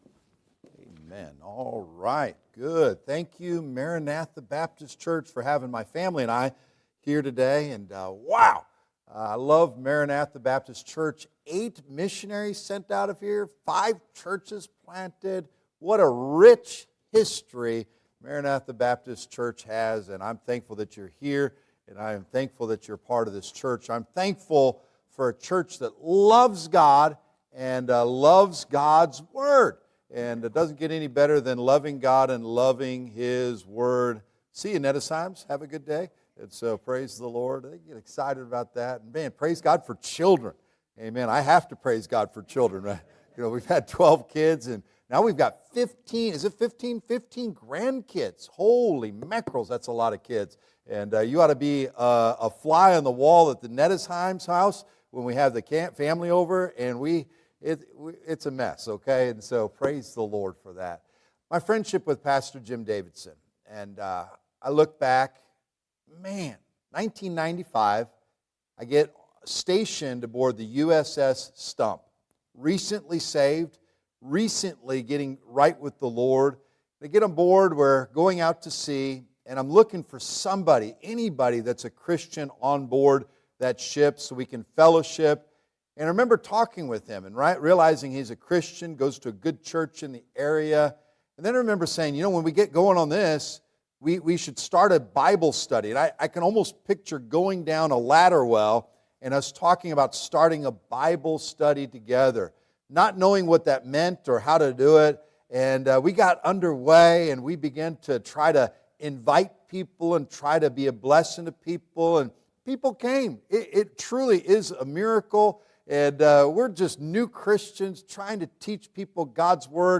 30th Homecoming – AM service “Why the Mess?”
Judges 21:3 Service Type: Sunday AM « 30th Homecoming